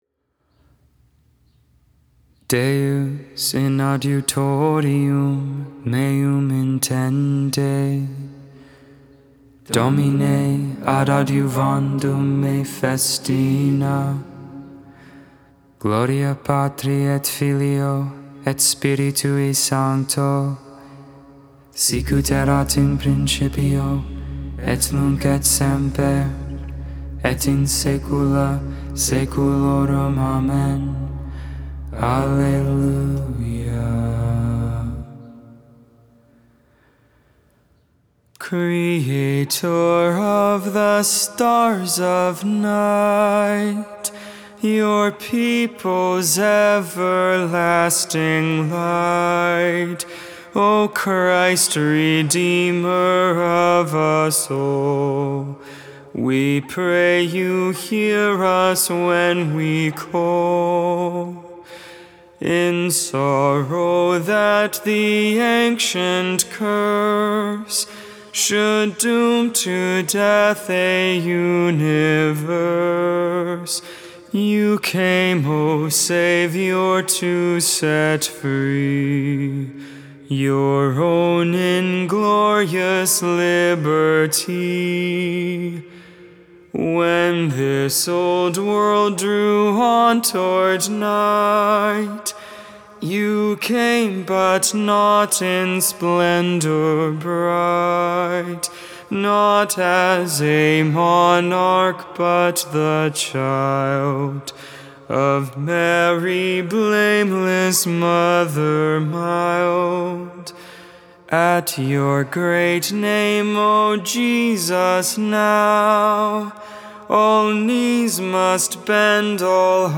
12.9.21 Vespers, Thursday Evening Prayer